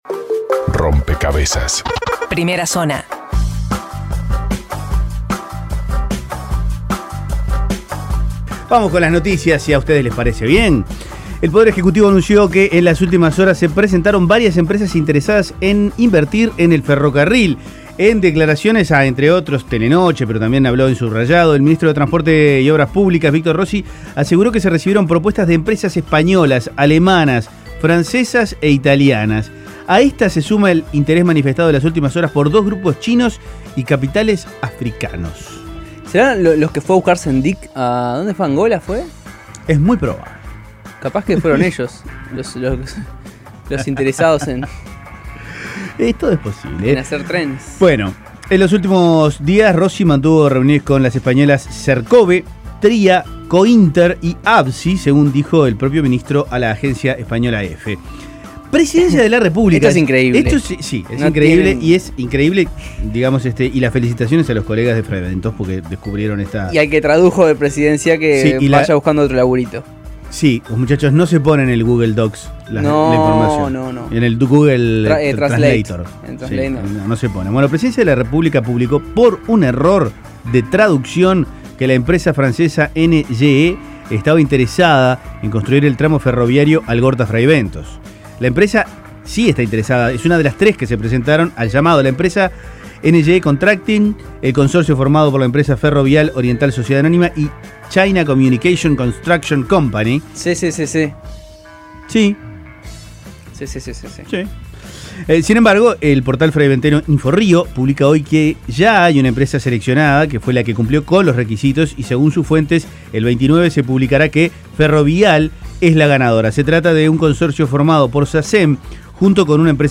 Resumen de Noticias